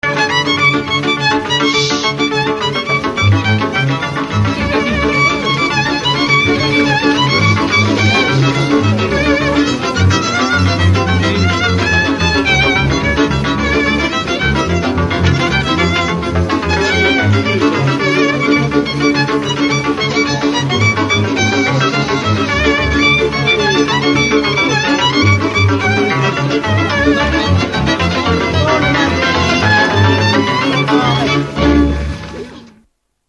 Dallampélda: Hangszeres felvétel
Dunántúl - Sopron vm. - Szany
Előadó: vonós zenekar, vonós zenekar
Műfaj: Friss csárdás
Stílus: 6. Duda-kanász mulattató stílus